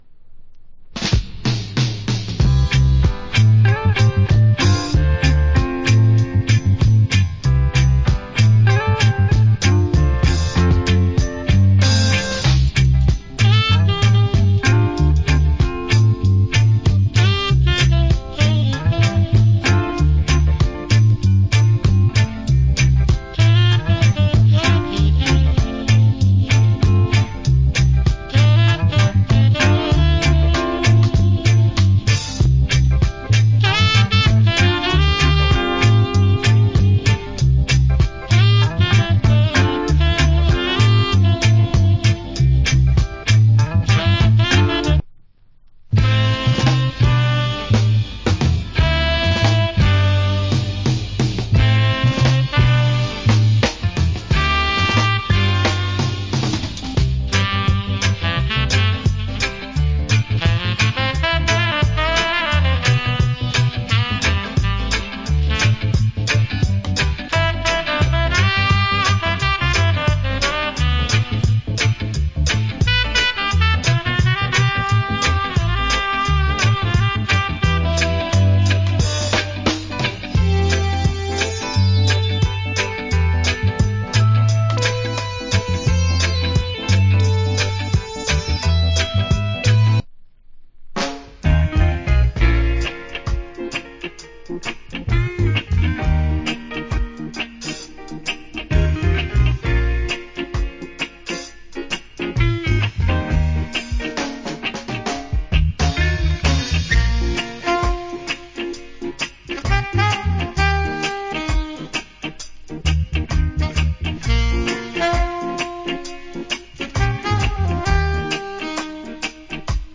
REGGAE INSTRUMENTAL
Good Reggae Instrumental Album.
70's